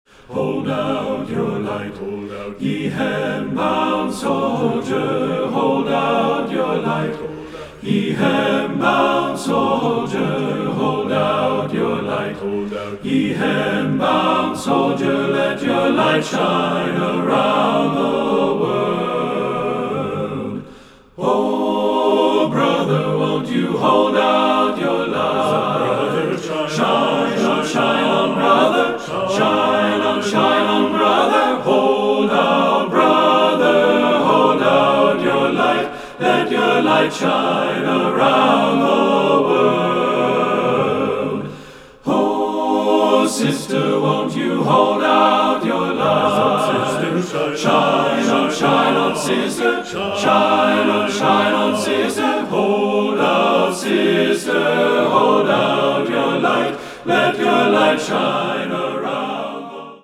Choral Male Chorus Spiritual
Traditional Spiritual
TTBB A Cap